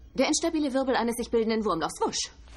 Samantha Carter erklärt den Begriff Kawoosh auf deutsch.